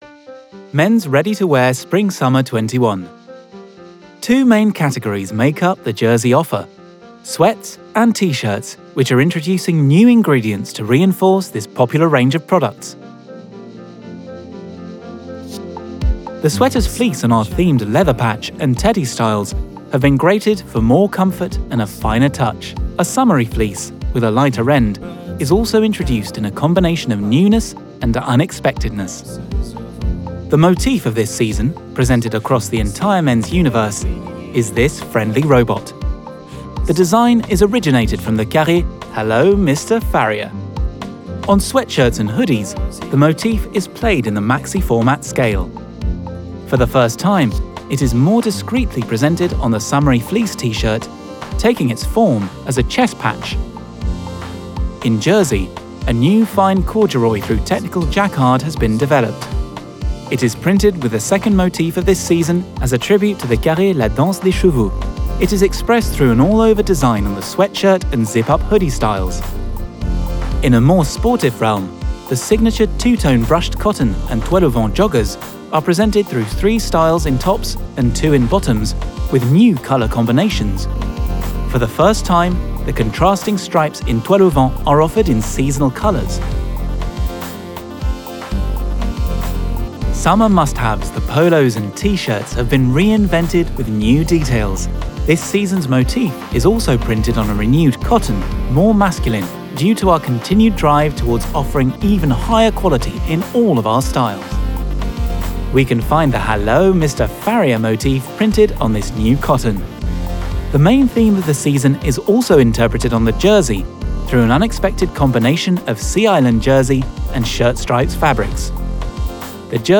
English (British)
Young, Natural, Versatile, Friendly, Corporate
Corporate